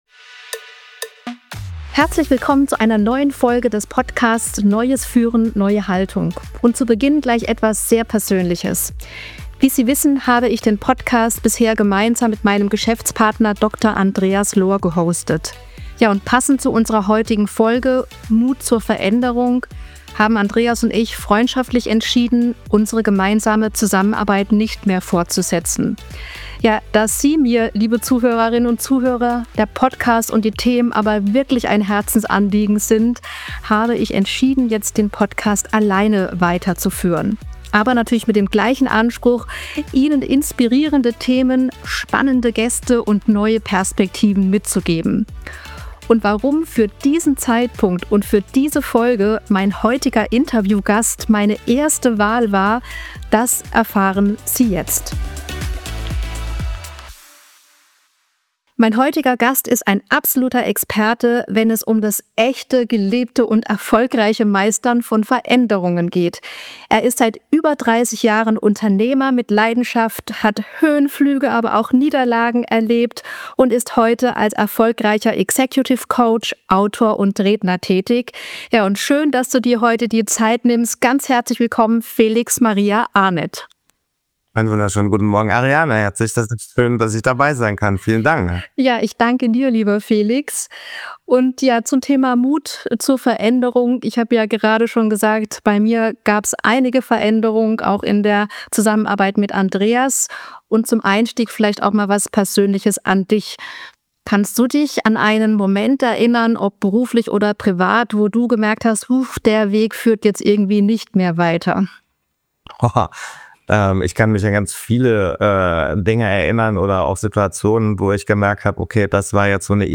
Im Gespräch geht es darum, wie Veränderung gelingt – sowohl im persönlichen als auch im unternehmerischen Kontext. Sie erfahren, warum Akzeptanz der erste Schritt zu echter Veränderung ist, wie Sie als Führungskräfte Mut zum Losgehen entwickeln und warum „Führung durch Wirkung“ wichtig ist.